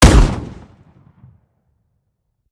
weap_romeo870_fire_plr_01.ogg